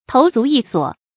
頭足異所 注音： ㄊㄡˊ ㄗㄨˊ ㄧˋ ㄙㄨㄛˇ 讀音讀法： 意思解釋： 見「頭足異處」。